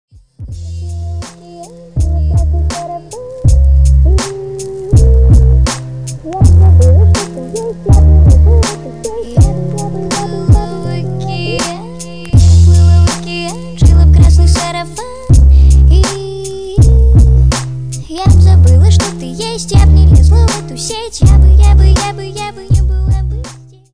• Качество: 128, Stereo
remix
спокойные
красивый женский голос
Bass Boosted